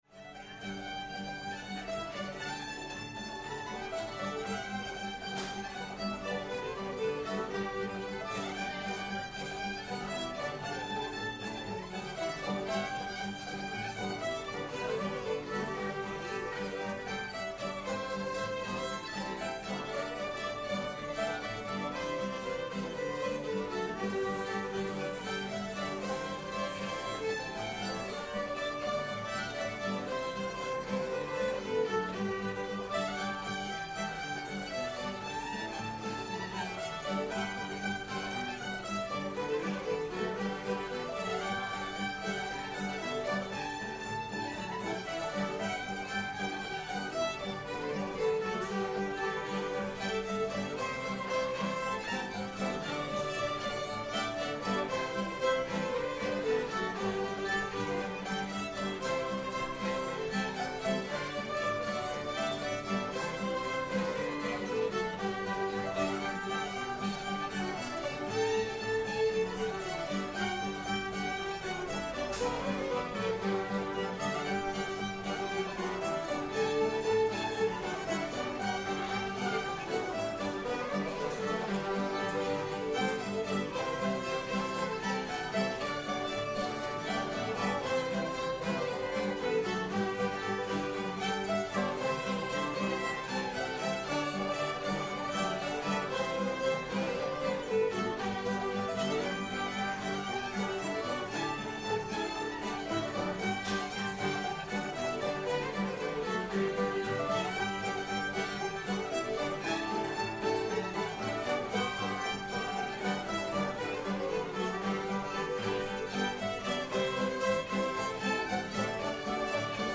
hell amongst the yearlings [G]